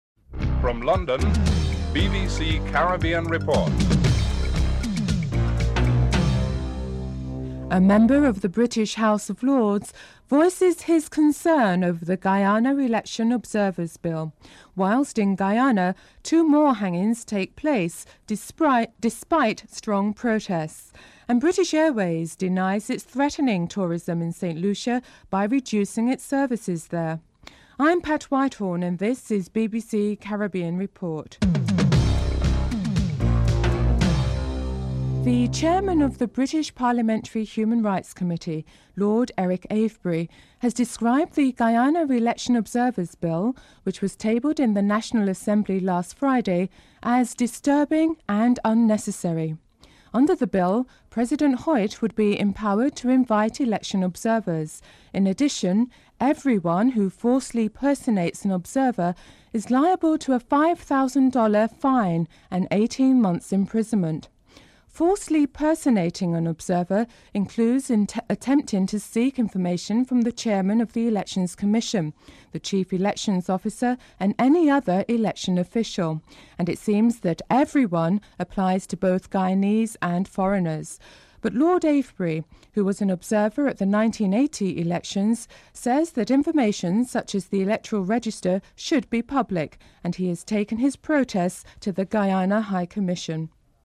1. Headlines (00:00-00:34)
4. Financial News (07:49-08:51)